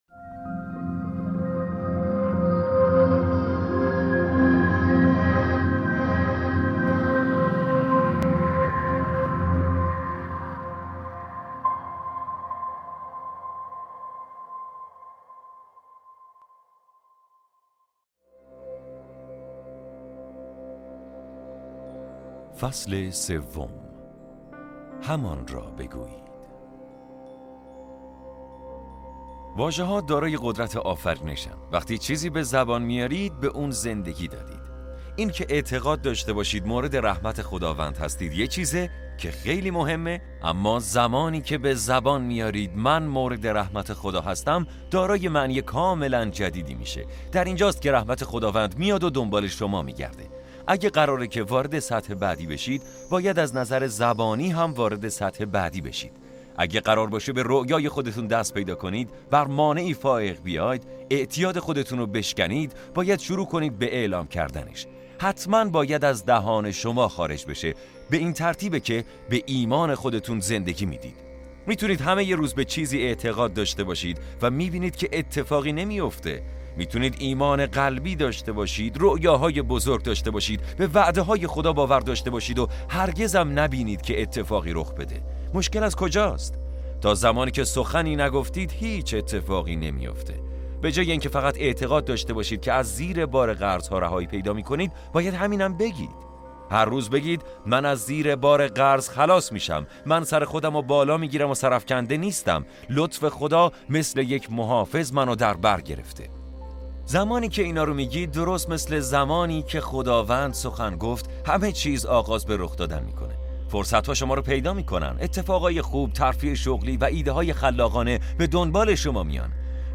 فصل سوم کتاب صوتی قدرت من هستم/ به جملات مثبت بیاندیشید و به زبان بیاورید!